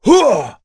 Bernheim-Vox_Attack4.wav